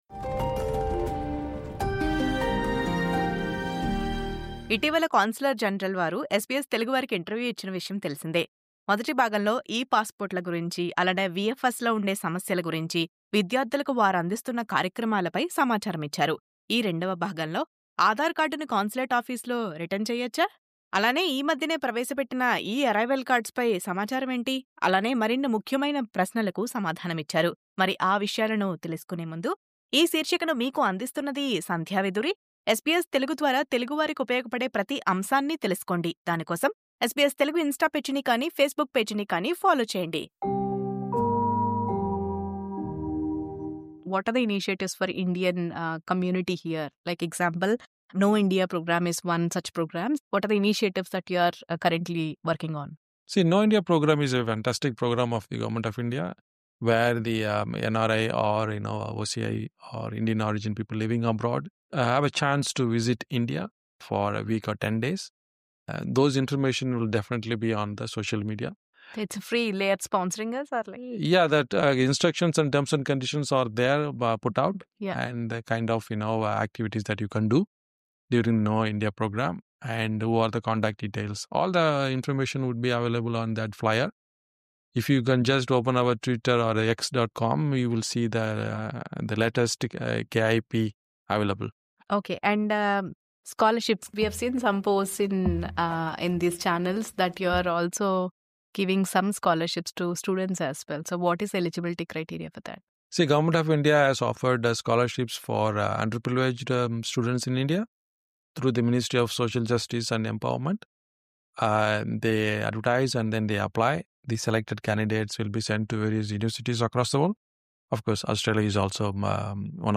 Dr. S. Janakiraman, Consul General of India, visited the SBS studios to discuss consulate matters and educate the community on the latest updates about e-passports, VFS issues, and other ongoing initiatives.